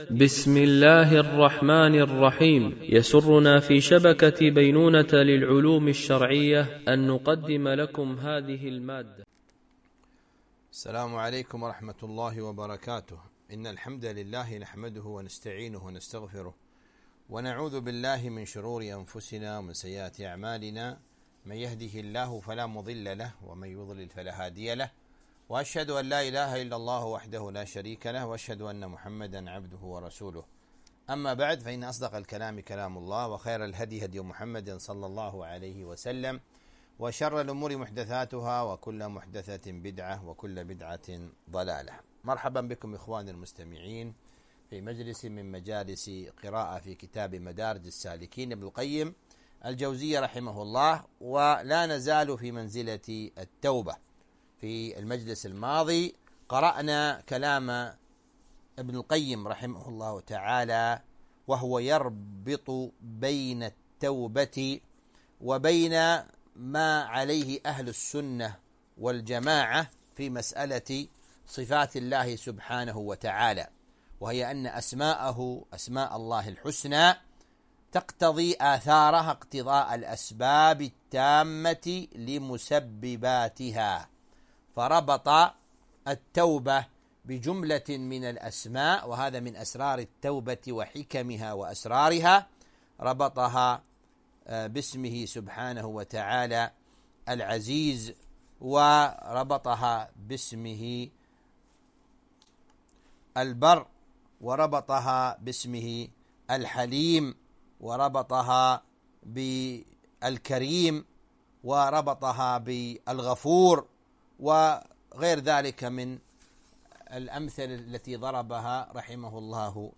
قراءة من كتاب مدارج السالكين - الدرس 27